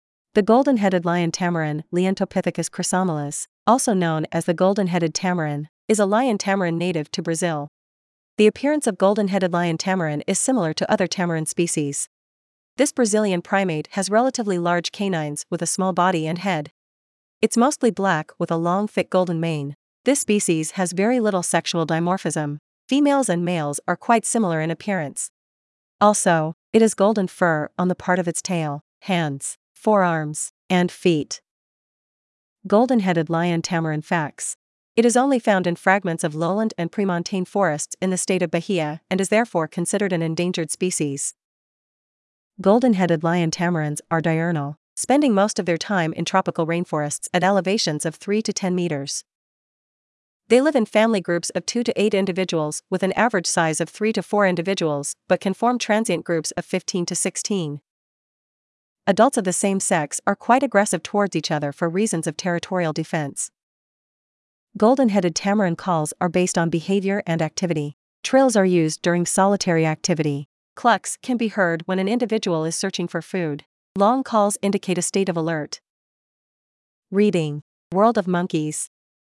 Golden-headed Lion Tamarin
Trills are used during solitary activity. Clucks can be heard when an individual is searching for food. Long calls indicate a state of alert.
Golden-headed-Lion-Tamarin.mp3